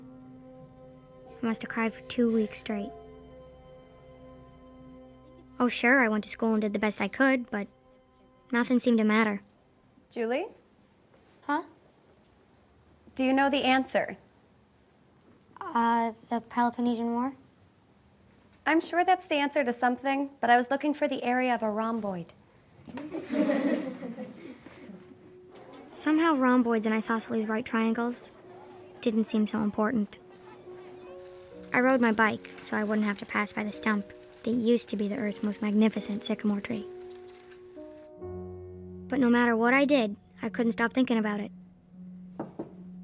Flipped 2010 We had a longer scene of that movie already in the math in movies collection. Here is just the scene with the rhomboid again, in better quality.